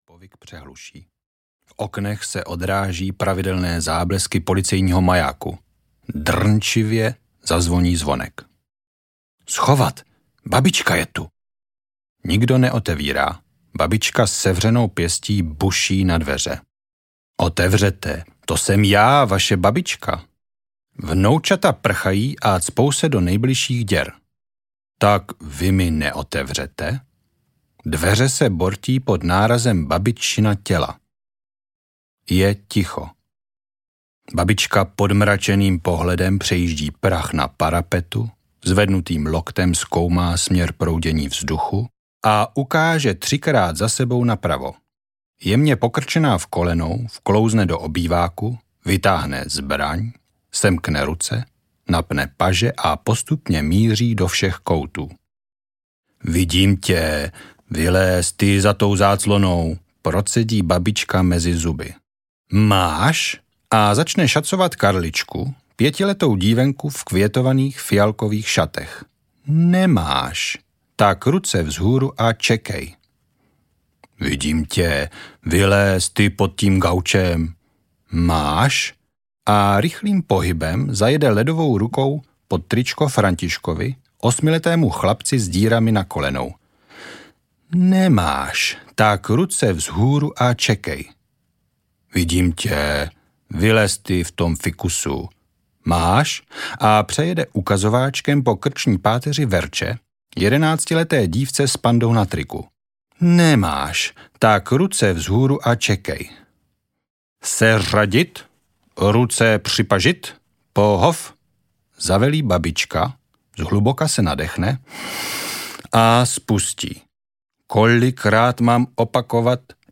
Ukázka z knihy
z-chlupate-deky-audiokniha